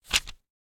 page-flip-20.ogg